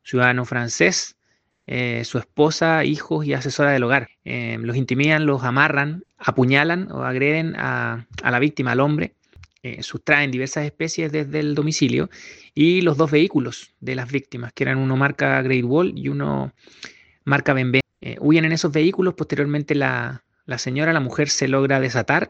El titular de la Fiscalía Metropolitana Centro Norte, Felipe Olivari Vargas, dijo que este hecho afectó a un ciudadano francés que fue apuñalado por uno de los asaltantes.